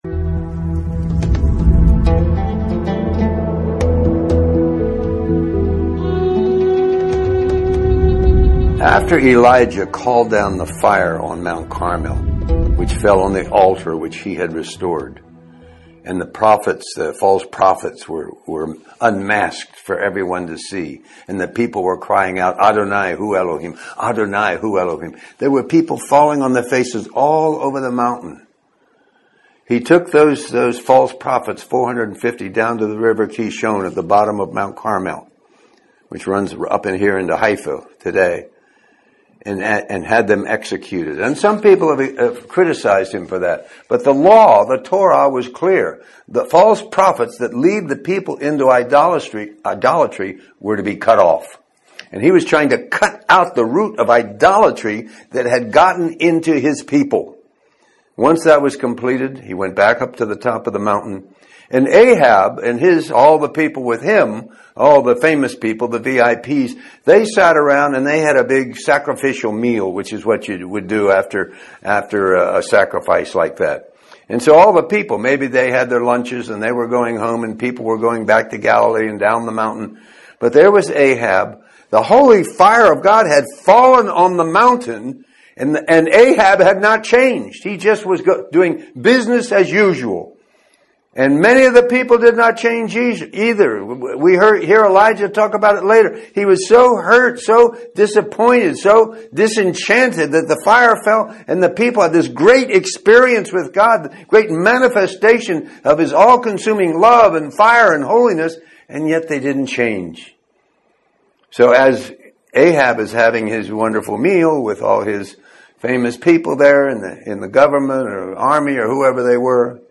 In this sermon, the speaker shares a story about General Booth, the founder of the Salvation Army, and his prayer for a move of God in France.